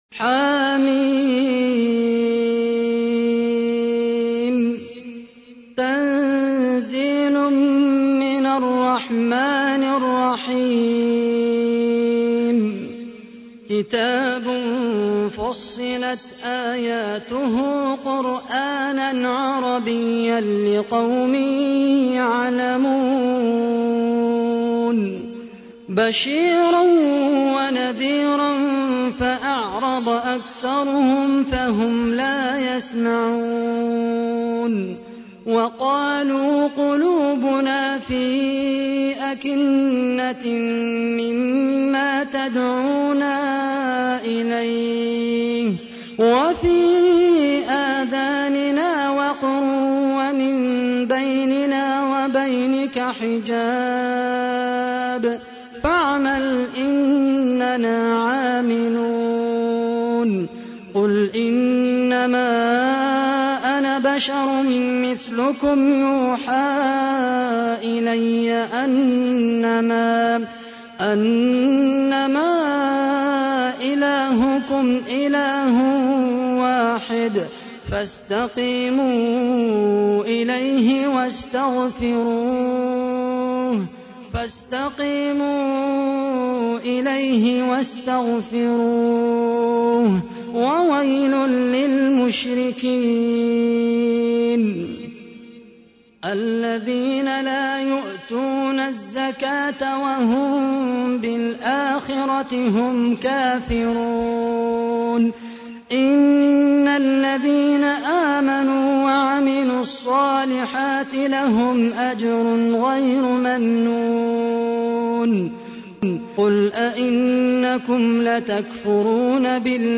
تحميل : 41. سورة فصلت / القارئ خالد القحطاني / القرآن الكريم / موقع يا حسين